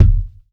impact_deep_thud_bounce_04.wav